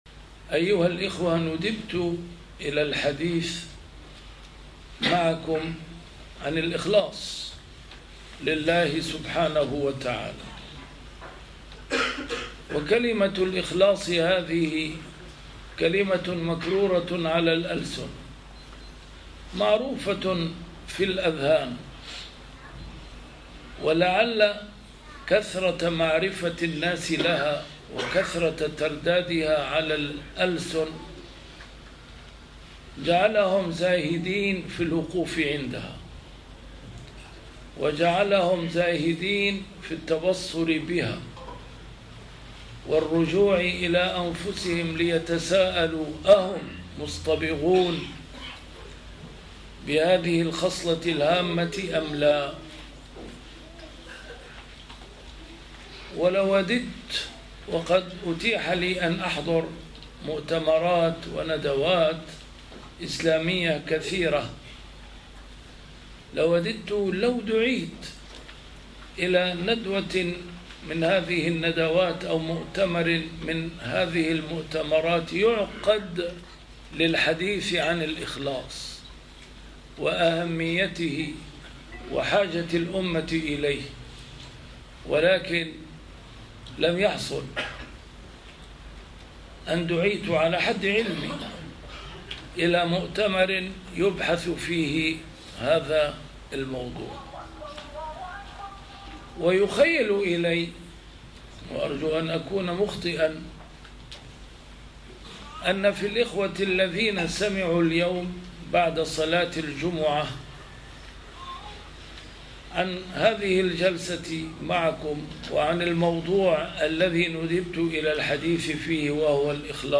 A MARTYR SCHOLAR: IMAM MUHAMMAD SAEED RAMADAN AL-BOUTI - الدروس العلمية - محاضرات متفرقة في مناسبات مختلفة - محاضرة في النمسا بعنوان: الإخلاص لله عز وجل